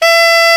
Index of /90_sSampleCDs/Roland L-CD702/VOL-2/SAX_Alto Short/SAX_A.ff 414 Sh
SAX A.FF E0J.wav